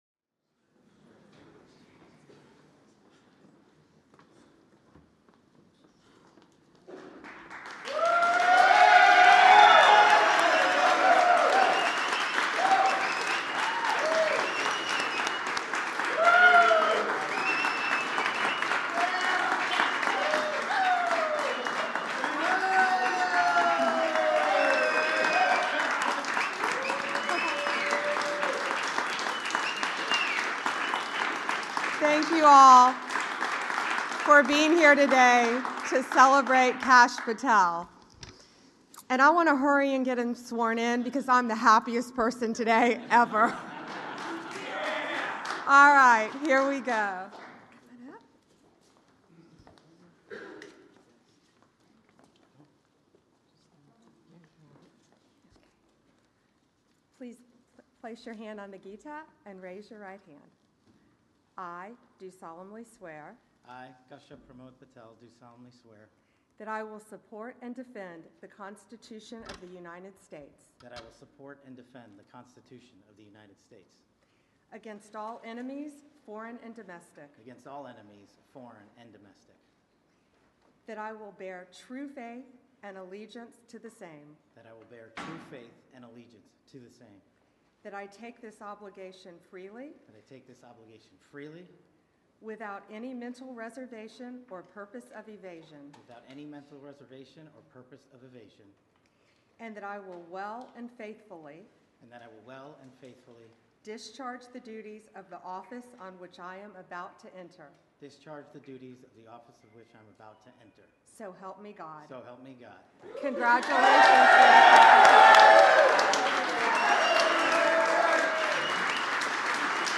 Swearing-In Ceremony of Kash Patel as Director of the Federal Bureau of Investigation